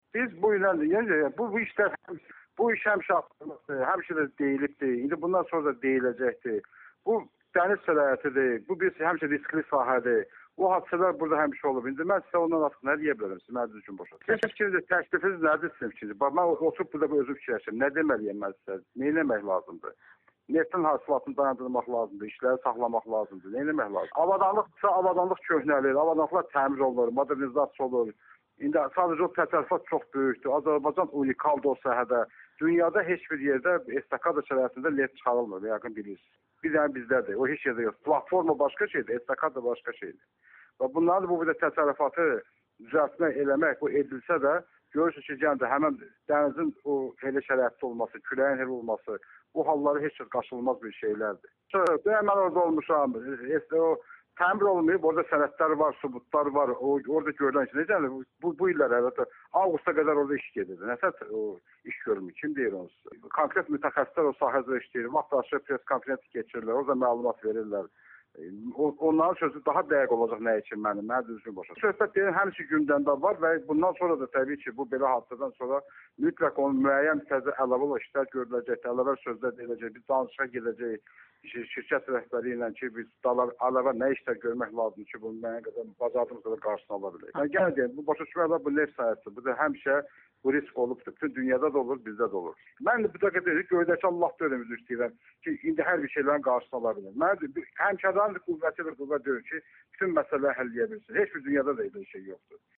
– (Несколько нервно)  У меня больше нет сил с вами говорить.